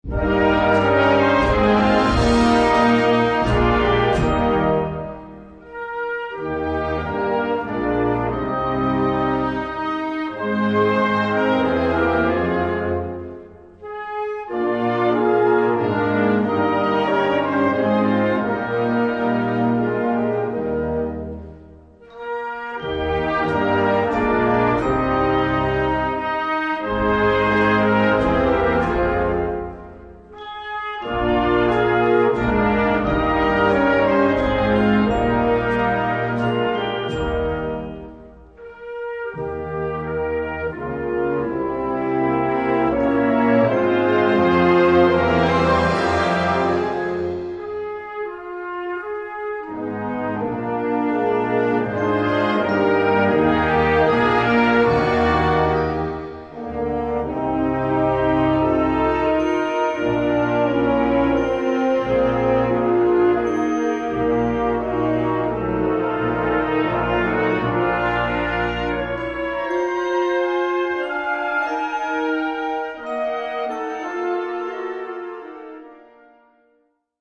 3:00 Minuten Besetzung: Blasorchester PDF